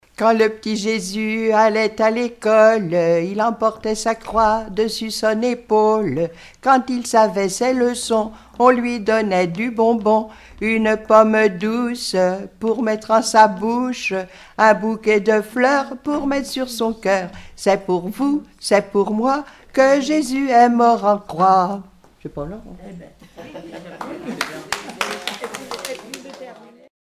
enfantine : prière, cantique
Collectif-veillée (2ème prise de son)
Pièce musicale inédite